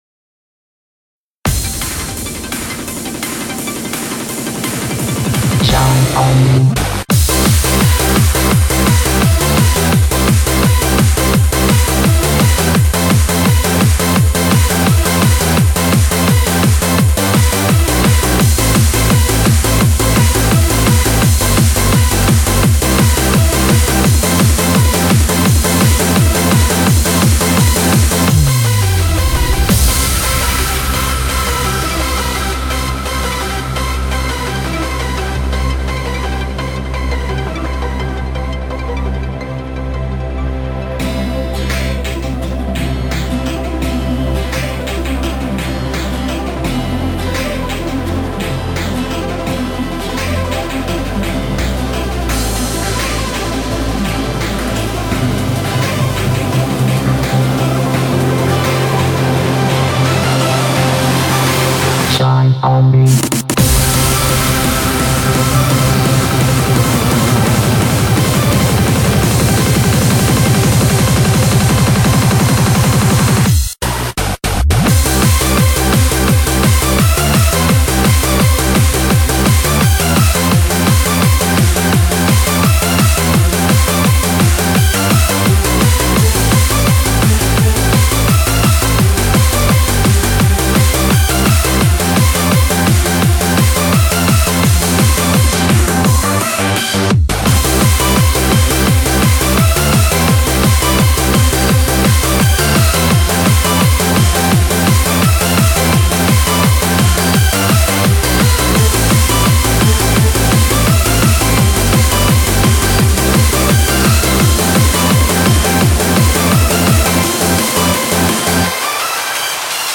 BPM170
Audio QualityPerfect (High Quality)
a pretty sweet happy hardcore style song